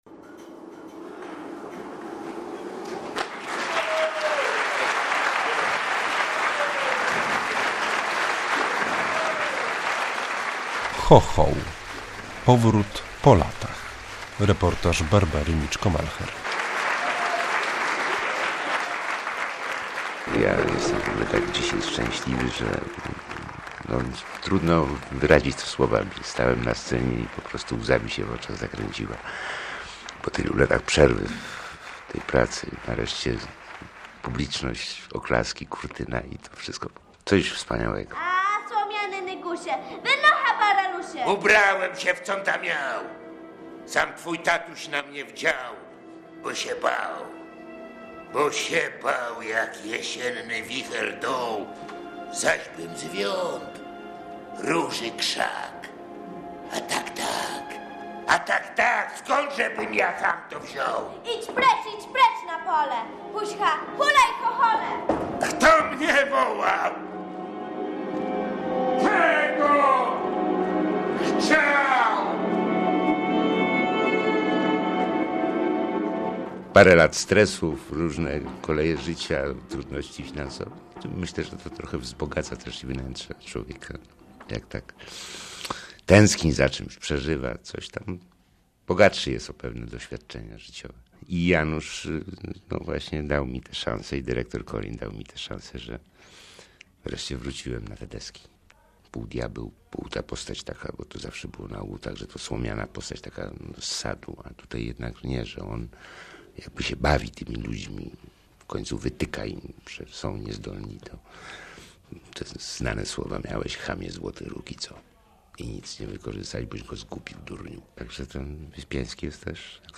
9o1cals4dh2s4ip_reportaz_chochol.mp3